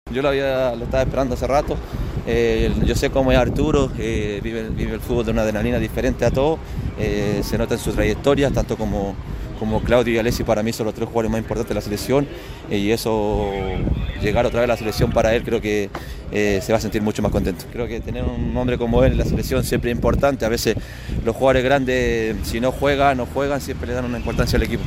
En diálogo con ADN Deportes, el lateral de Colo Colo valoró la convocatoria del volante a la selección chilena y anticipó el trascendental duelo ante Perú por las Eliminatorias.